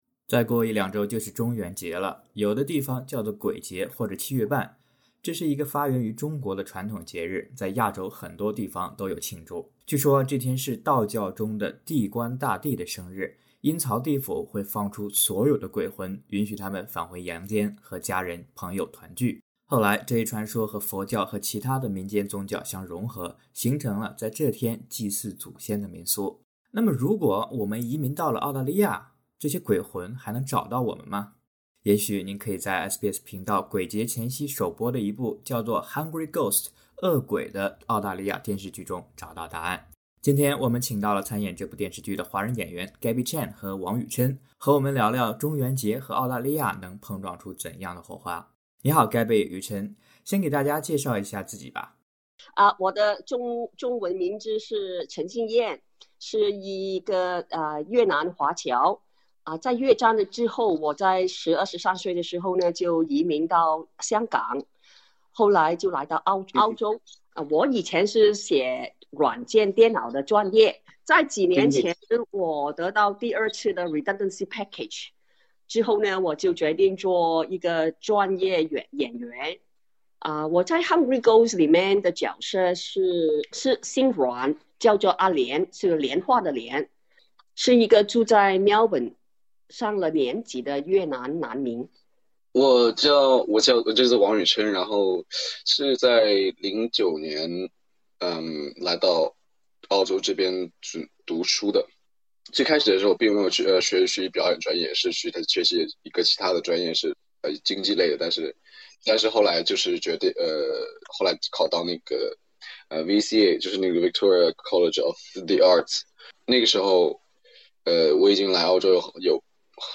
《Hungry Ghosts》演员专访：澳洲荧幕上的亚洲恐怖故事